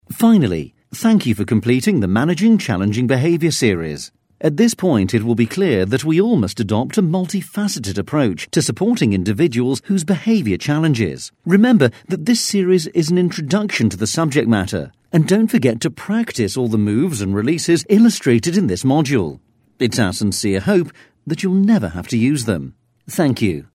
Friendly, natural yet corporate sounding neutral British English voice.
Sprechprobe: eLearning (Muttersprache):
Friendly, natural corporate neutral UK English.